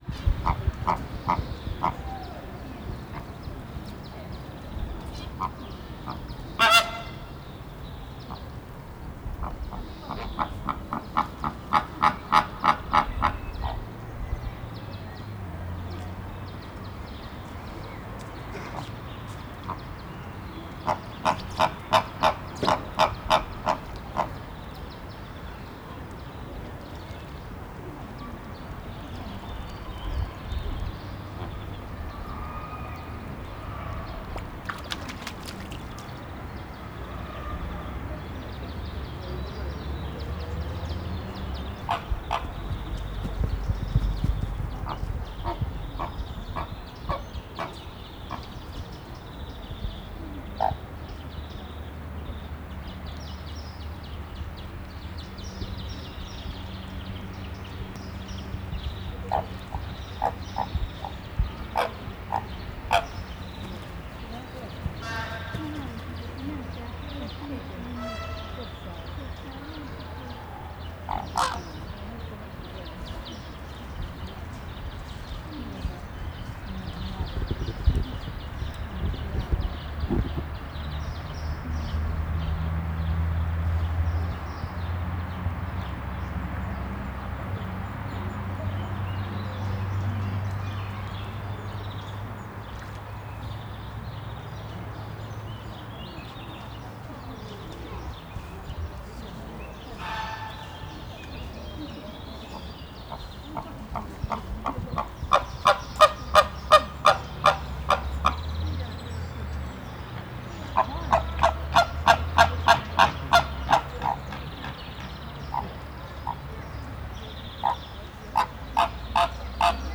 rozsasflamingo02.08.wav